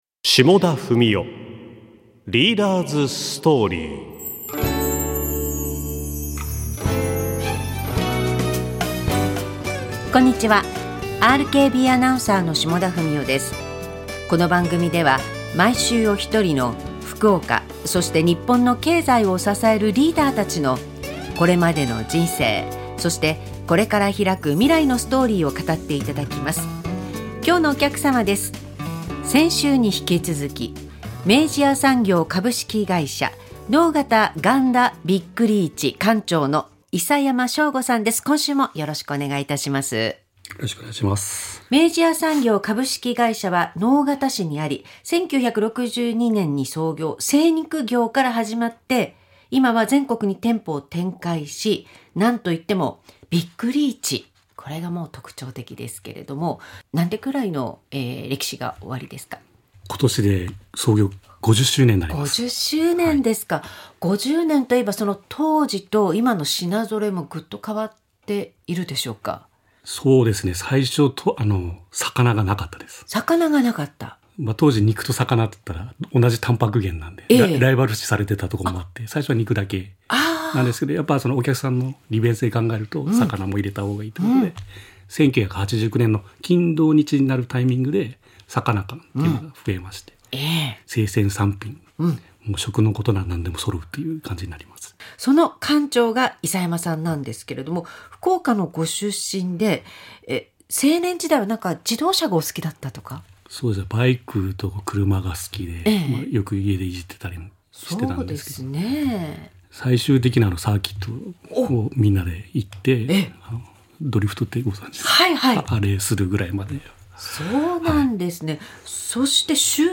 ラジオ